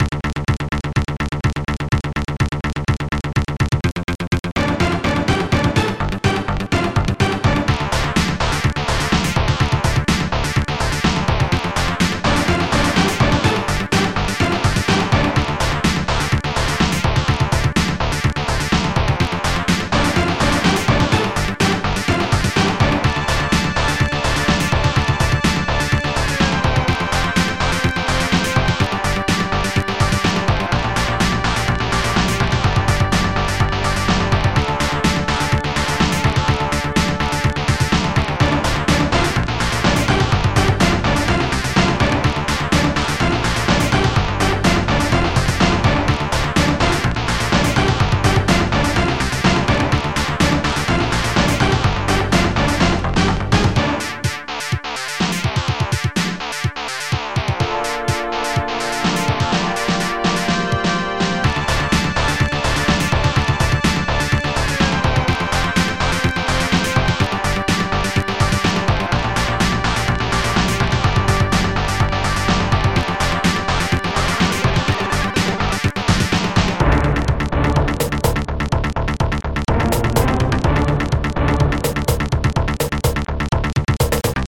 st-01:AcidBD1 st-01:19Snare st-01:HackBass1 st-01:HackBass2 st-01:Hardsynth2 st-01:BlastCon st-01:SDIstrings